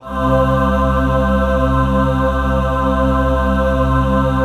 DM PAD4-08.wav